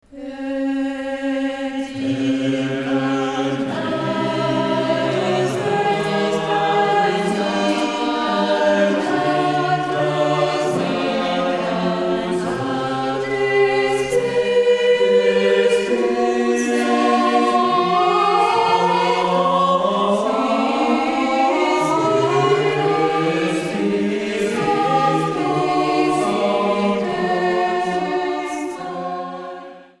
Chants de Noël
chantent à 4 voix